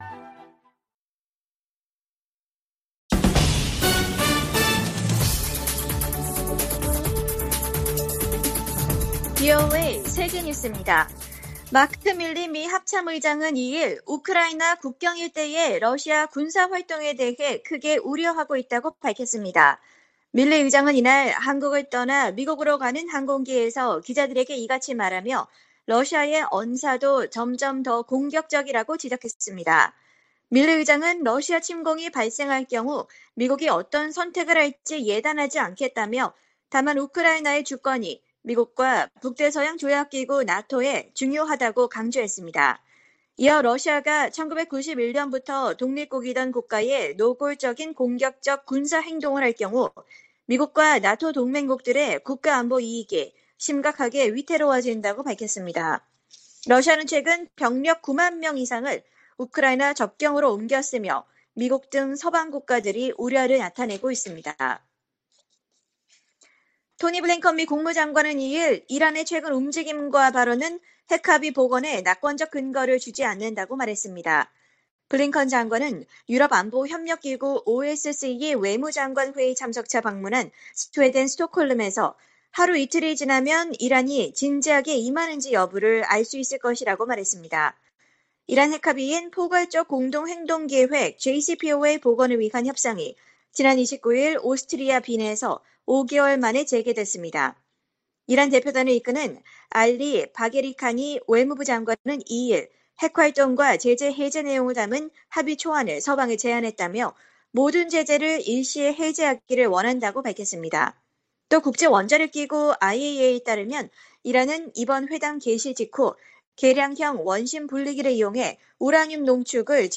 VOA 한국어 간판 뉴스 프로그램 '뉴스 투데이', 2021년 12월 3일 2부 방송입니다.